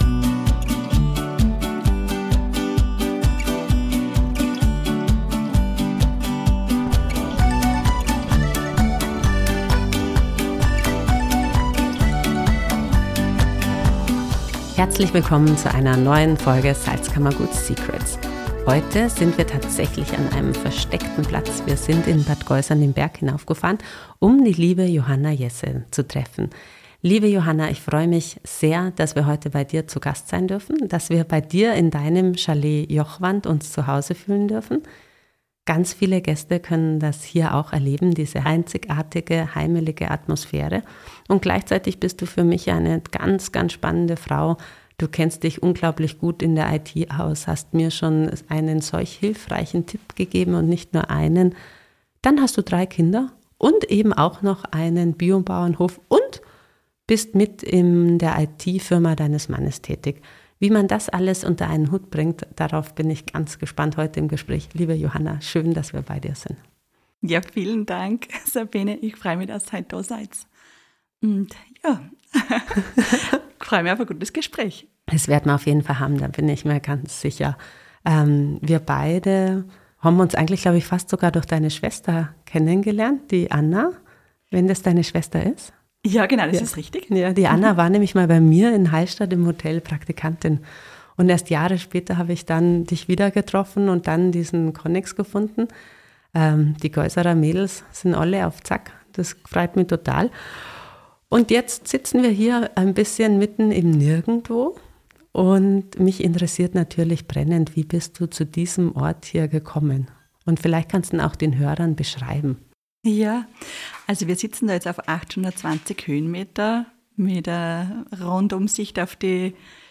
Ein ehrliches Gespräch über den Mut, Hilfe anzunehmen, die Sehnsucht nach Erdung und warum es sich lohnt, den Weg bis ganz nach oben auf den Berg zu wagen.